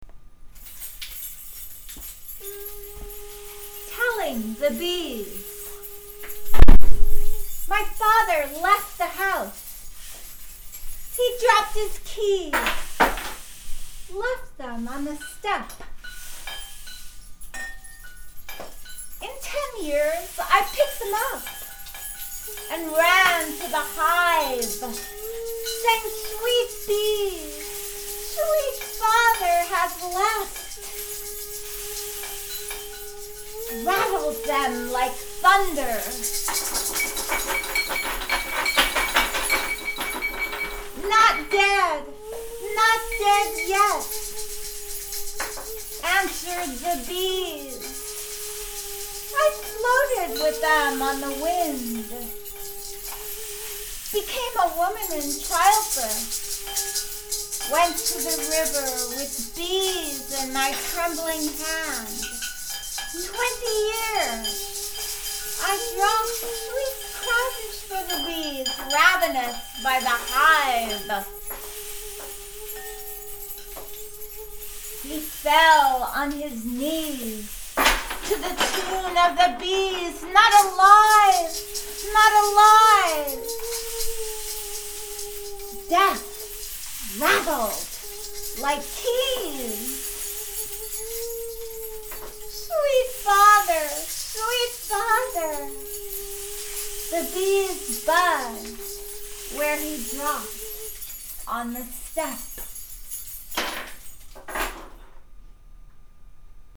house keys, slammed door, and other percussion instruments